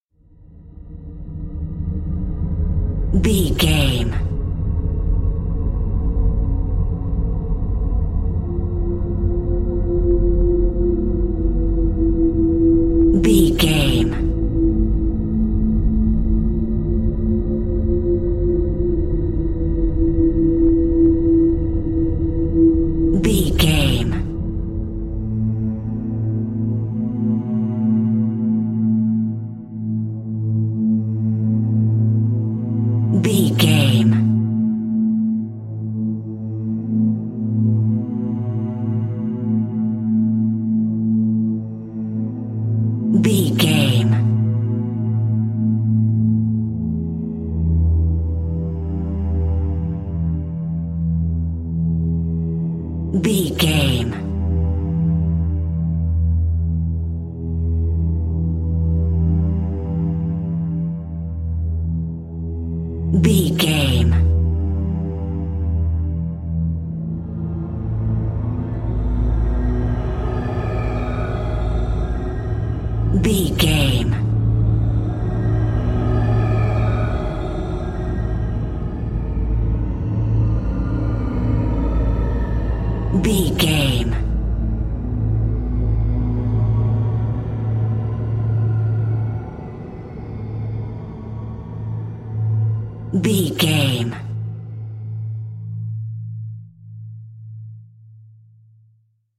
Thriller
Aeolian/Minor
Slow
piano
synthesiser
electric piano
ominous
dark
suspense
haunting
creepy